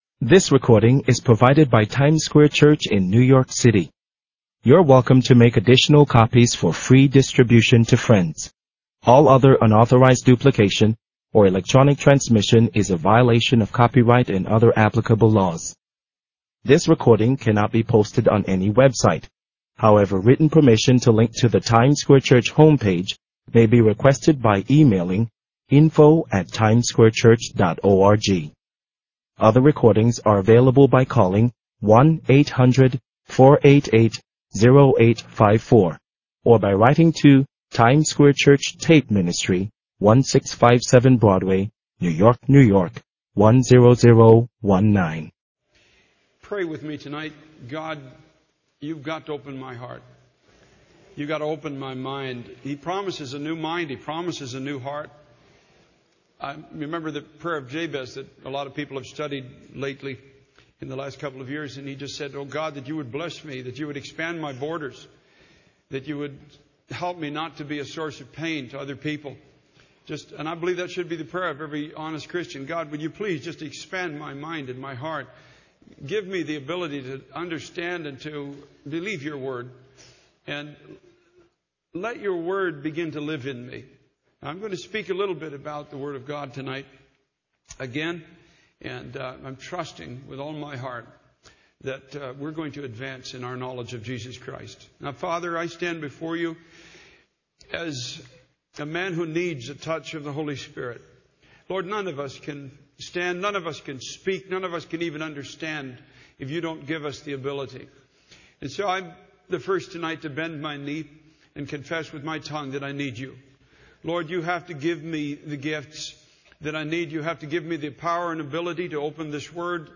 In this sermon, the speaker emphasizes the power of God's word to transform and reward individuals.